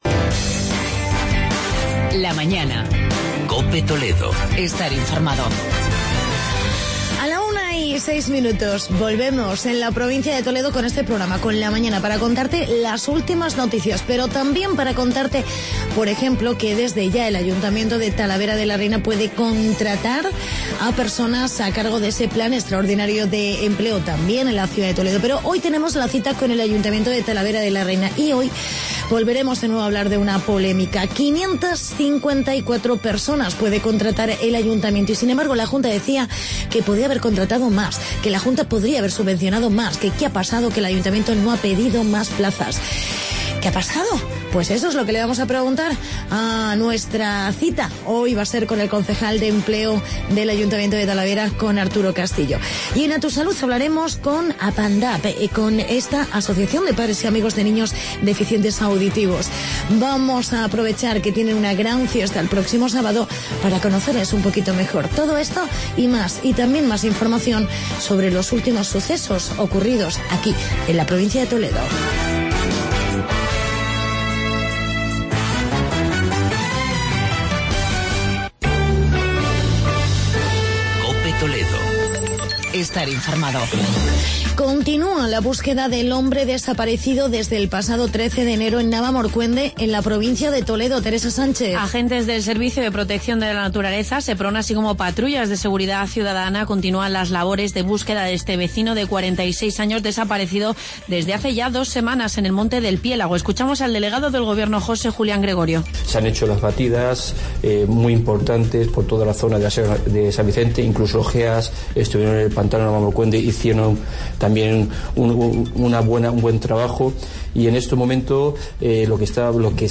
Entrevista con el concejal de empleo, Arturo Castillo